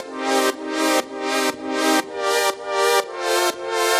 Index of /musicradar/french-house-chillout-samples/120bpm
FHC_Pad A_120-C.wav